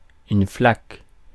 Ääntäminen
Synonyymit flache gloye nickel Ääntäminen France: IPA: /flak/ Haettu sana löytyi näillä lähdekielillä: ranska Käännös Substantiivit 1. puddle 2. pool Suku: f .